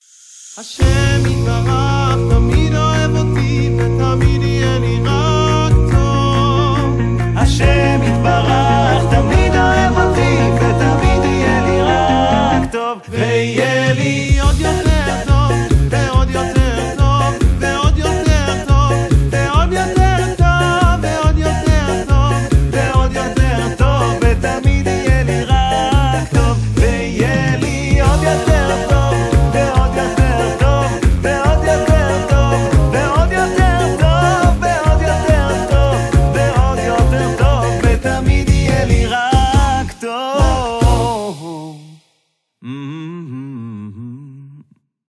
מחרוזת שמחה ותוססת